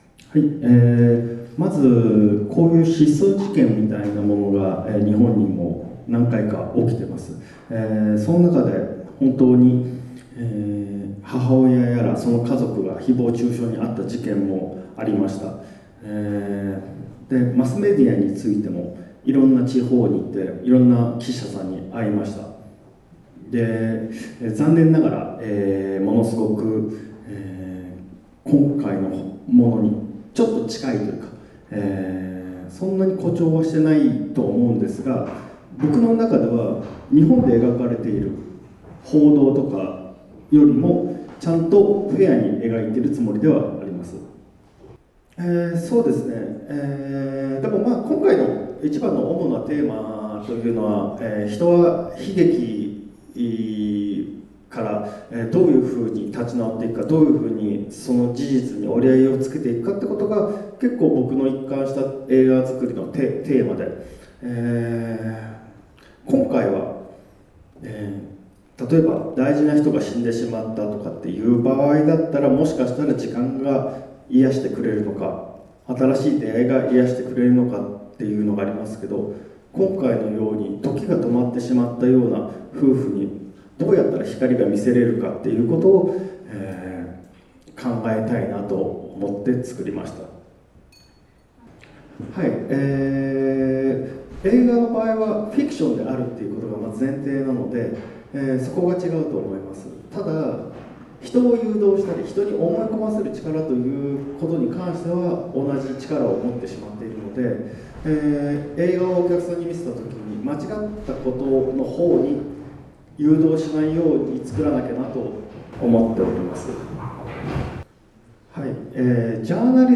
Filmgespräch